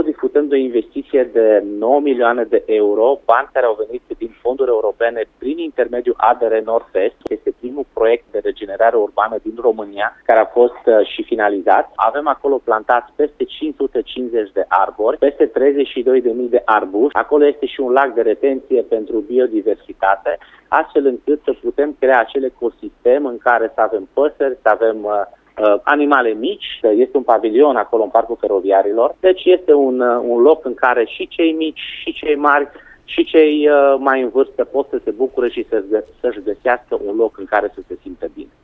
Detalii despre Parcul Feroviarilor am aflat de la viceprimarul Clujului.
Viceprimarul Dan Tarcea a adus mai multe detalii la Radio Cluj: